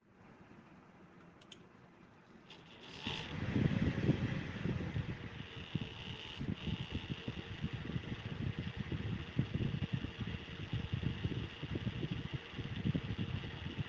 Grafikkarte Rattert unter Last
Wenn ich die Lüfterdrehzahl nach oben drehe fängt sie an zu Rattern.
Hab mal eine Aufnahme gemacht da hört man das Geräusch.